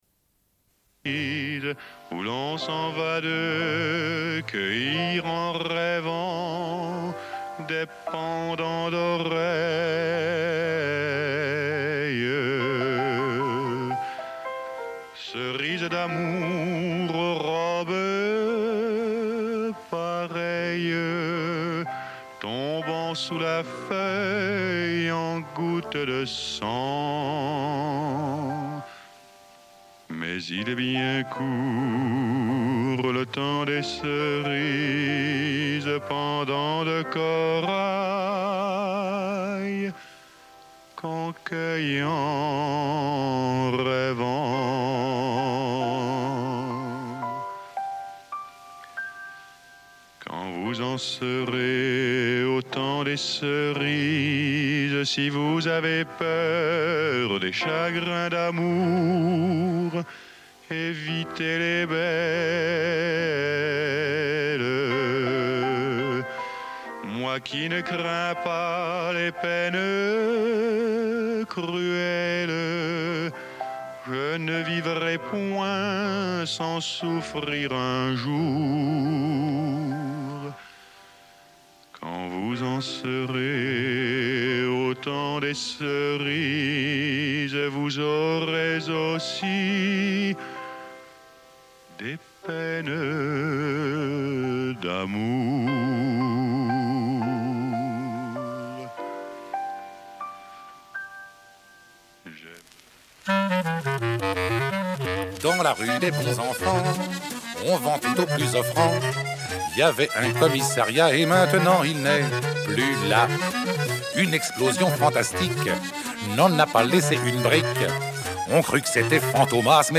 Une cassette audio, face A31:22